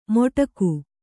♪ moṭaku